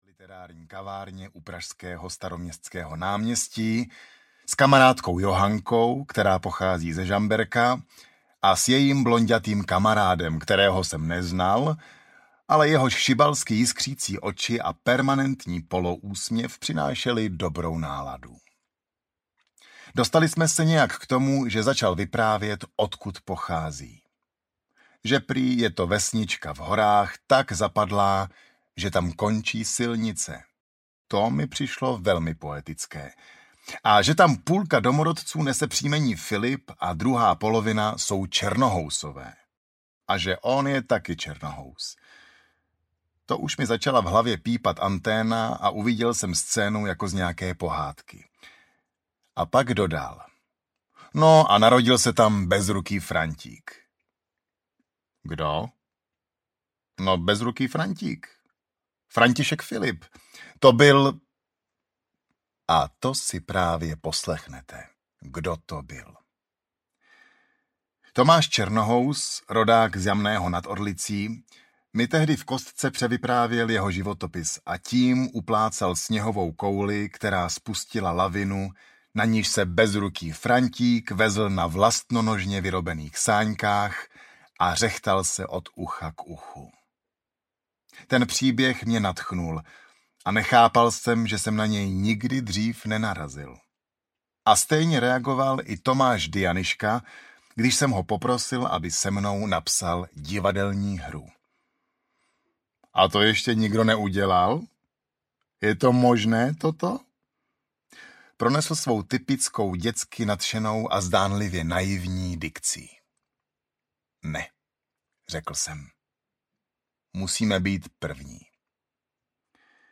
Bezruký Frantík audiokniha
Ukázka z knihy